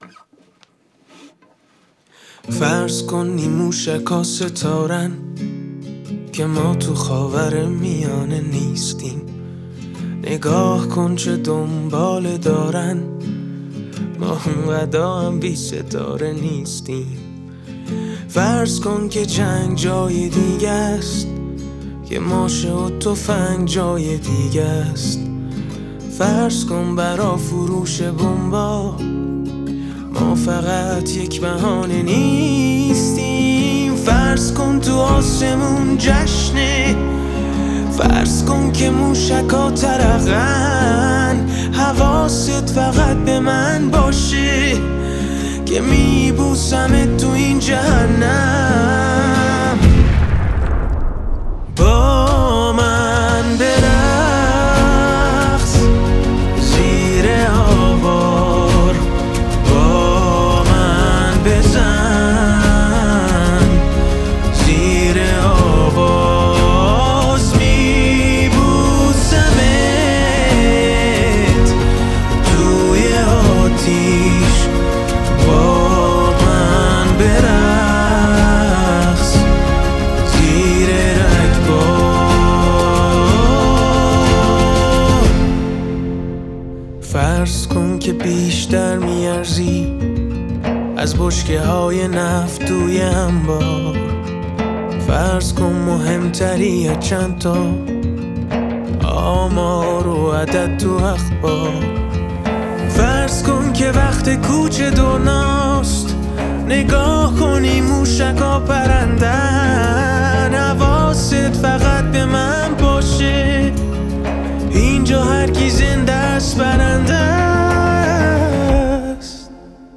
صدایی دلنشین